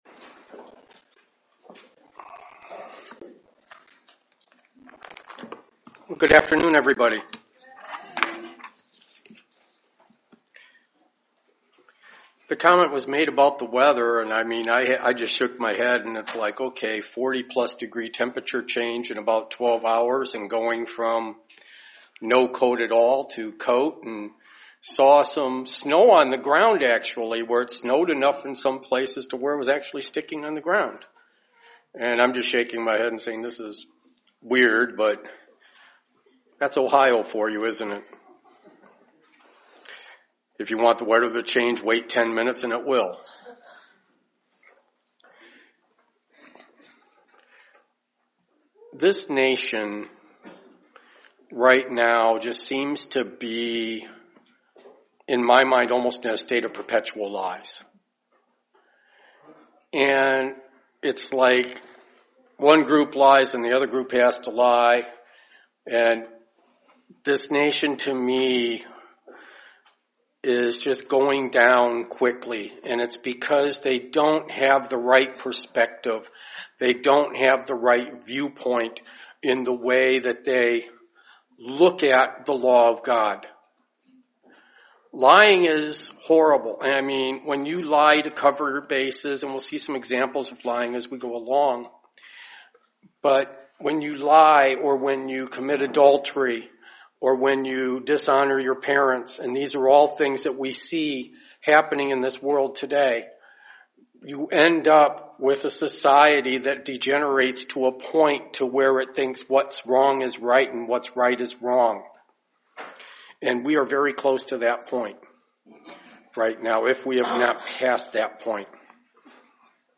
This sermon will go through a few examples in the bible of people who have repented. Also, listen to discover what a repentant attitude is along with who/what is able to repent.
Given in Dayton, OH